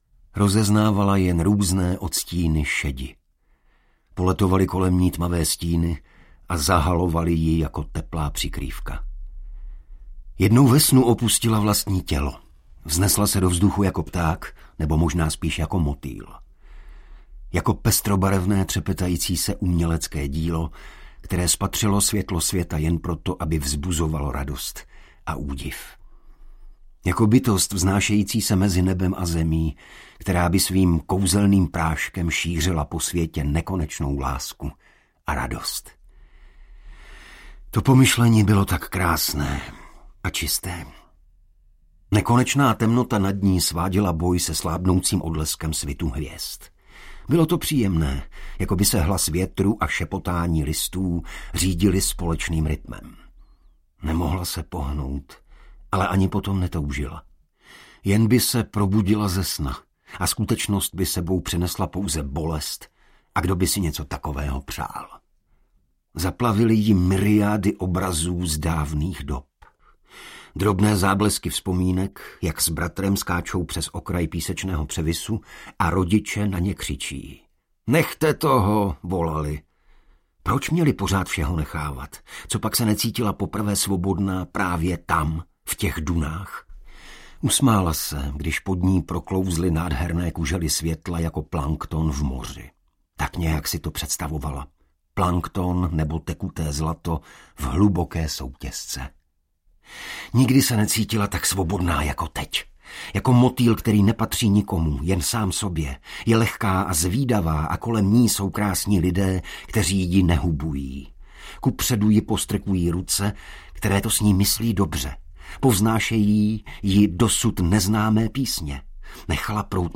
Nesmírný audiokniha
Ukázka z knihy
• InterpretIgor Bareš